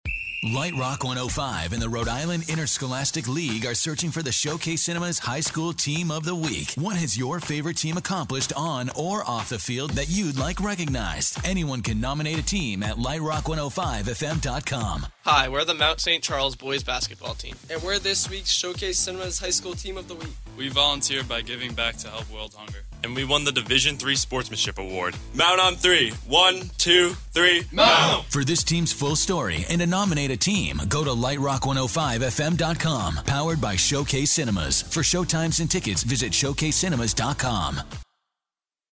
Lite Rock 105 On-Air Spot